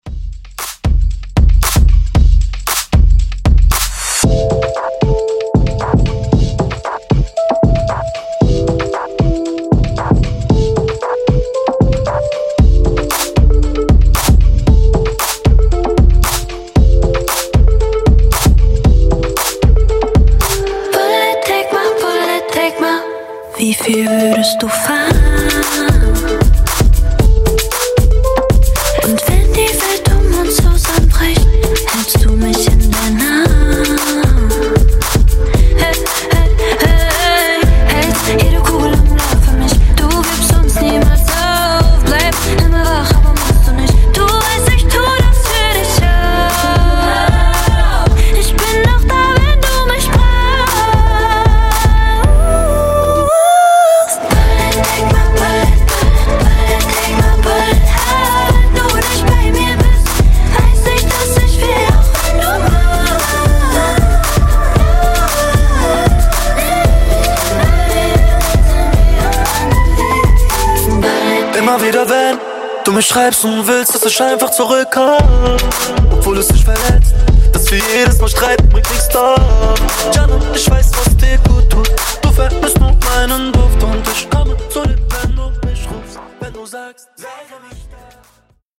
Genres: 80's , DANCE , RE-DRUM
Clean BPM: 128 Time